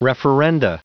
Prononciation du mot referenda en anglais (fichier audio)
Prononciation du mot : referenda